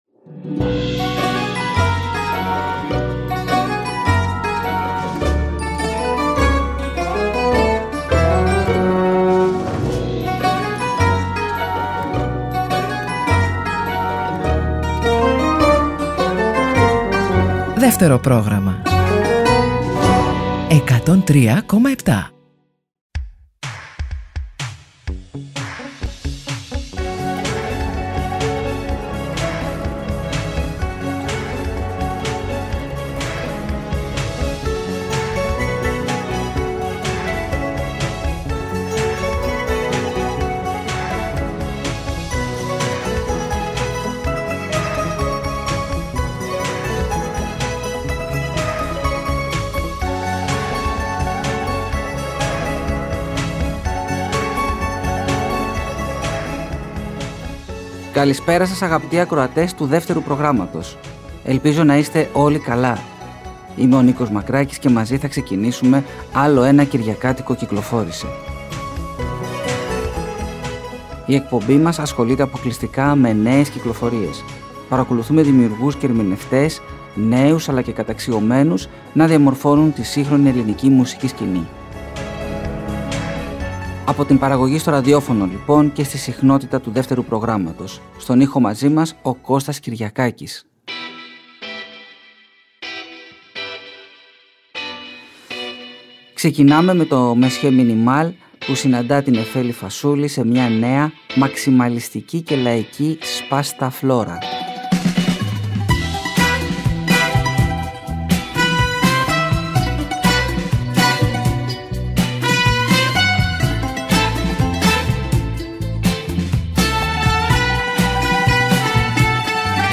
Μία ακόμα εκπομπή με θέμα τις νέες κυκλοφορίες προστίθεται στο Δεύτερο Πρόγραμμα 103,7.